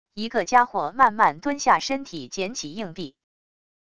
一个家伙慢慢蹲下身体捡起硬币wav音频